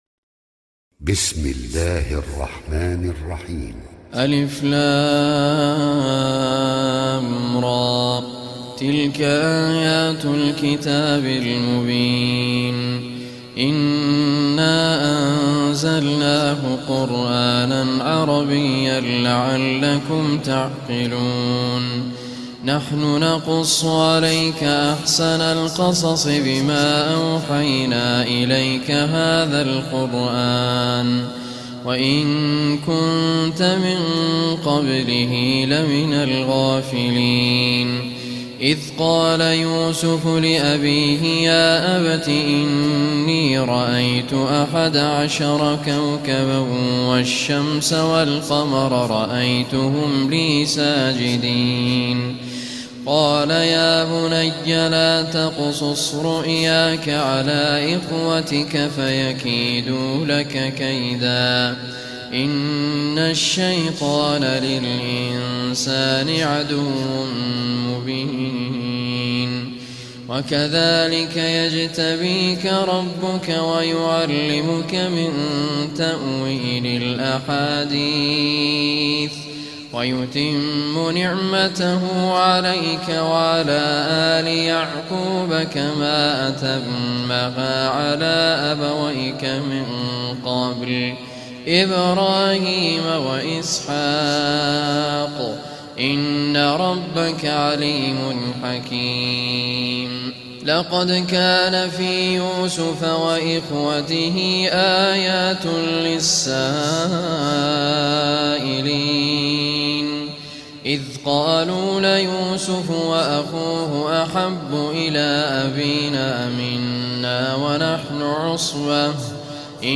Surah Yusuf Recitation